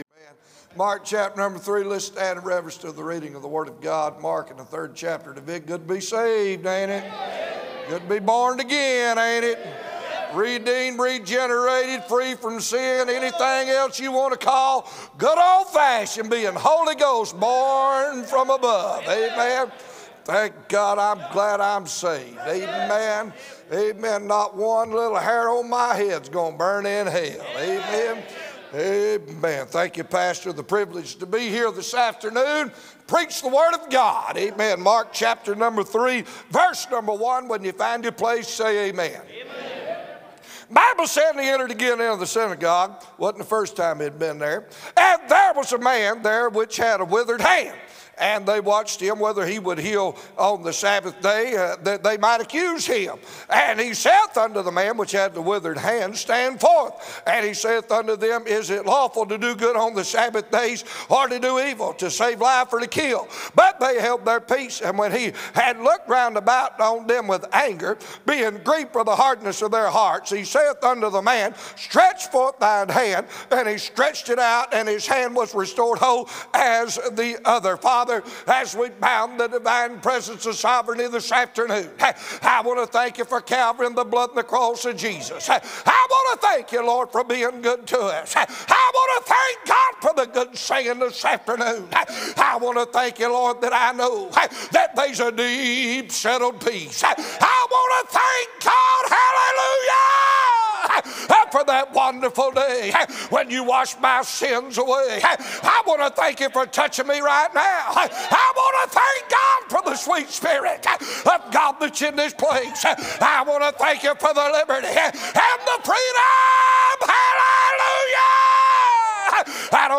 A message from the series "2025 Spring Jubilee."